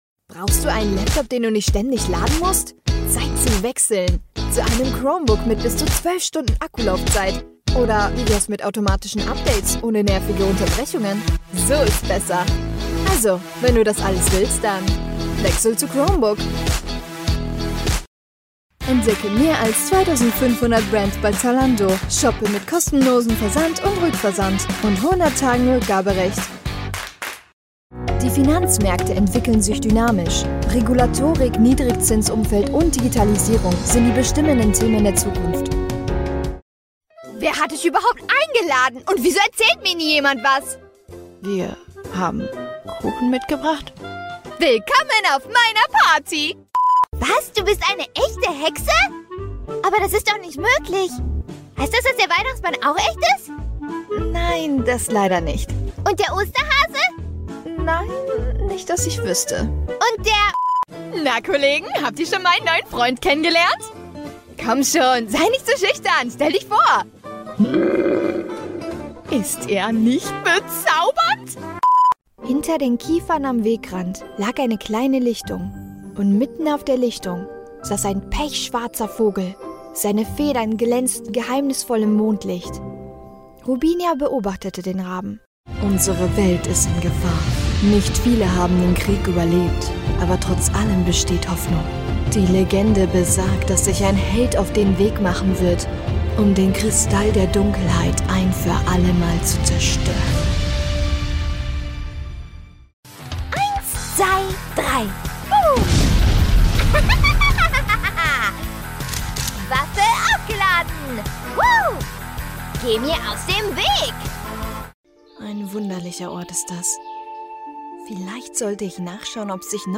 SPRACHAUFNAHMEN
Erweitern Sie Ihr 3D-Video mit einer professionellen sprachlichen Vertonung, die perfekt auf Ihre Bedürfnisse zugeschnitten ist.